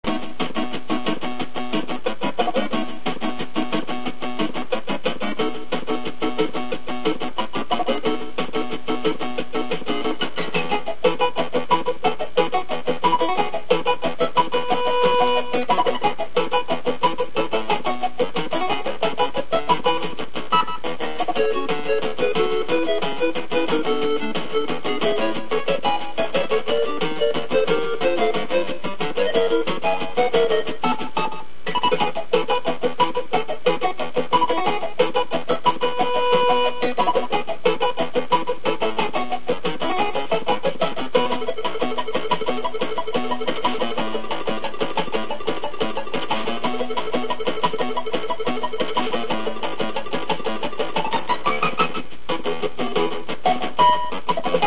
They are now in mono sound at 32khz, 64kbps in .mp3 format.